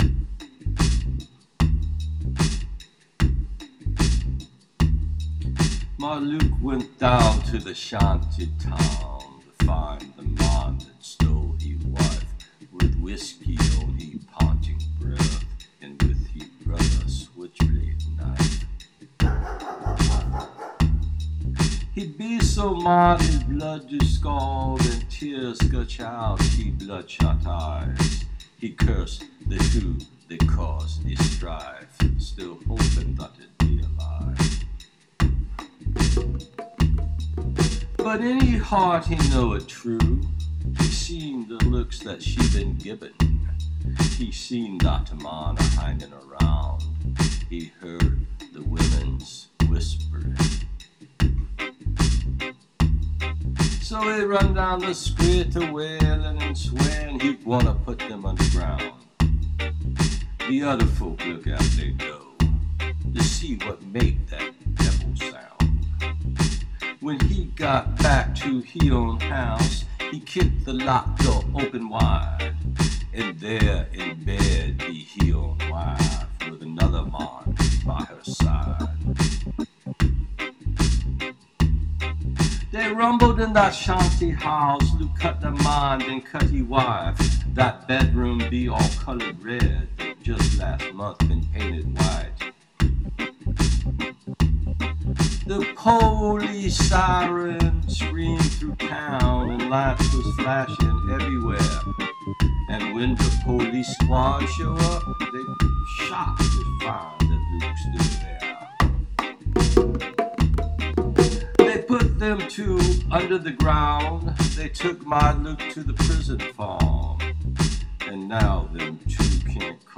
Here’s the dub version of “Nassau Street Song,” copyrighted in 1987 by University of South Carolina Press